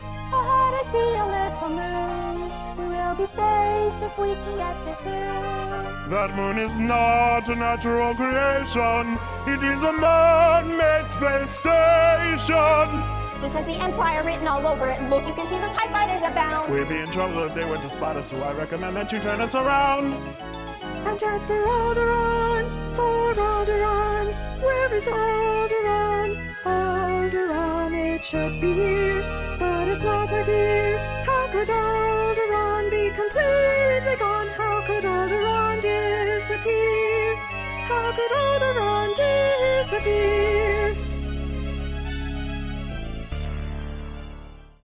Hear bars 34-58 with Han singing